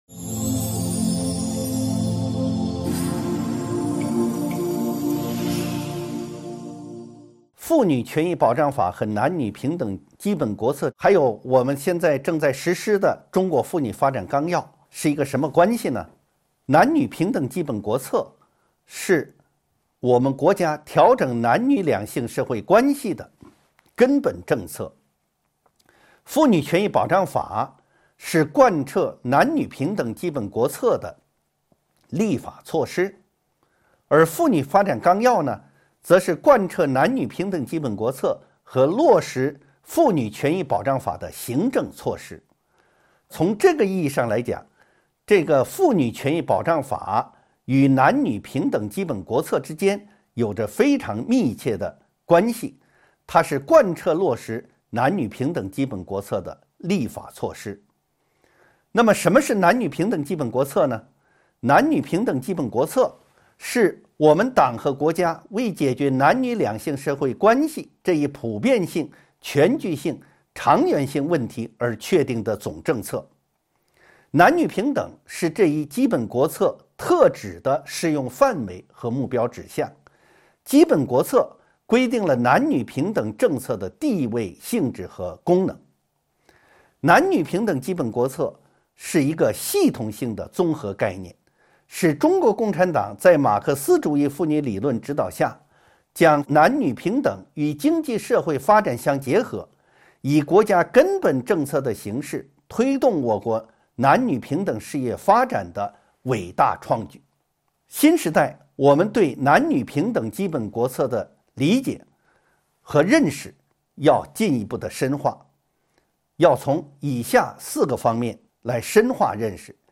音频微课：《中华人民共和国妇女权益保障法》3.妇女权益保障法与贯彻落实男女平等基本国策